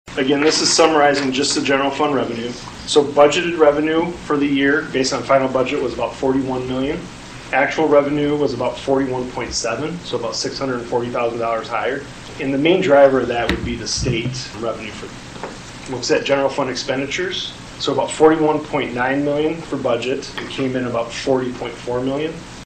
During a recent Board of Education meeting